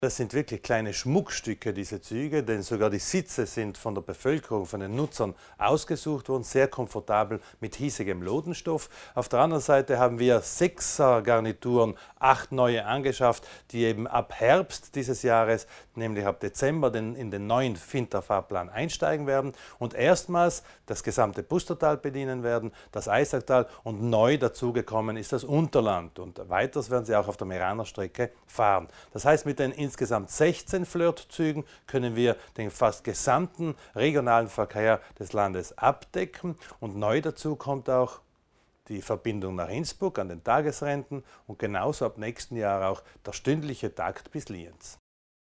Landesrat Widmann über die Vorteile der neuen Züge